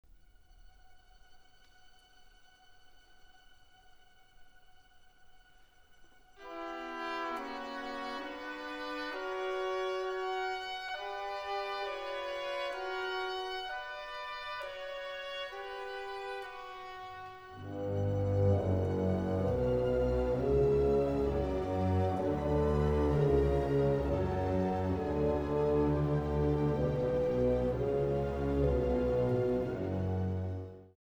Trascrizione per orchestra di Maurice Ravel